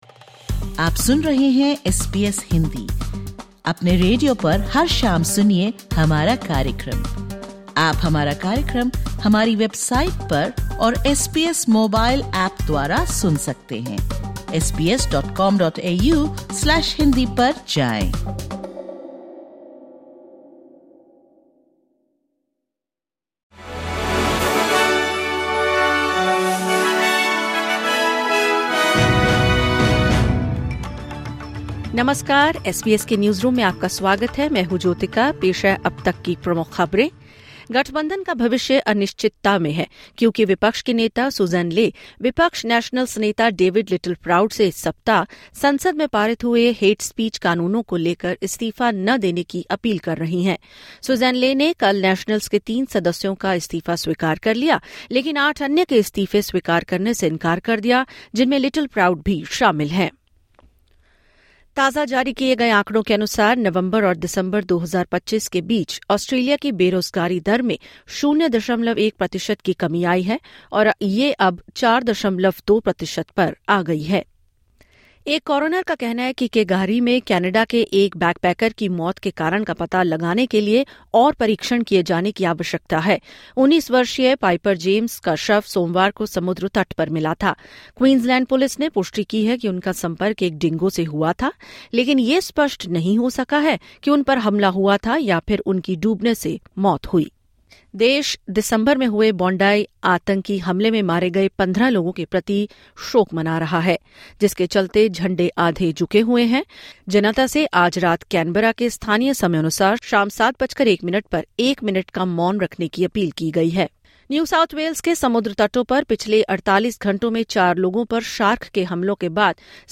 ऑस्ट्रेलिया और भारत समेत दुनिया भर से 22/01/2026 के प्रमुख समाचार हिंदी में सुनें।